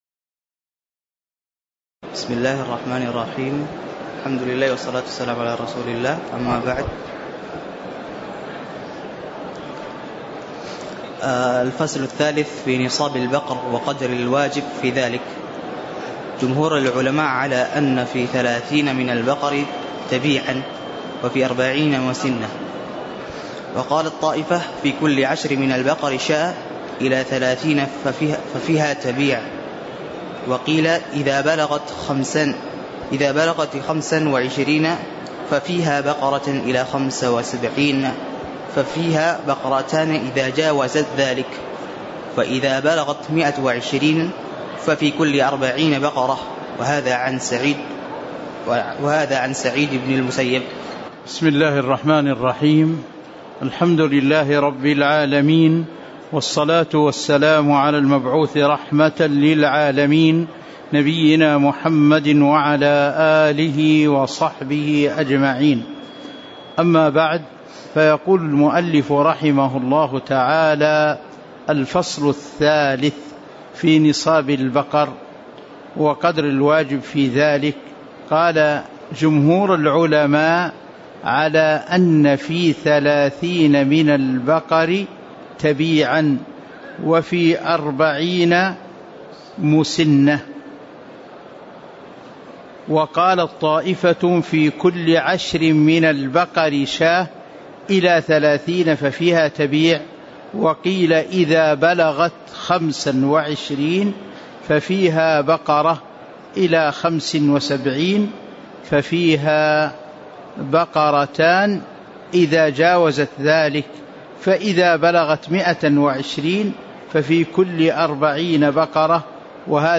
تاريخ النشر ٢٣ رجب ١٤٤٥ هـ المكان: المسجد النبوي الشيخ